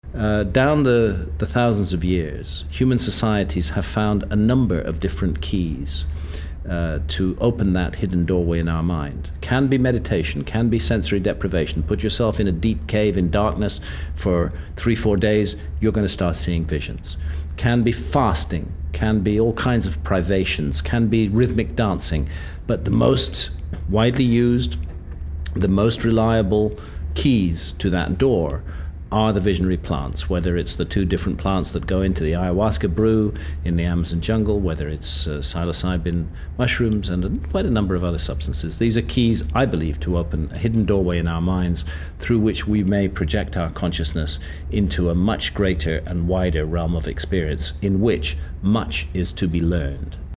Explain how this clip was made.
Then as the chairs were being folded and with only minutes before leaving for the airport, he graciously agreed to sit down with me and field a few questions: